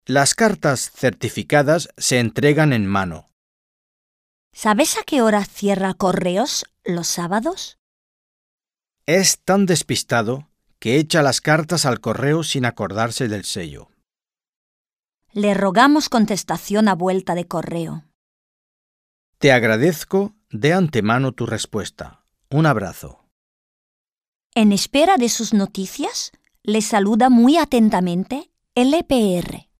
Un peu de conversation - Le courrier postal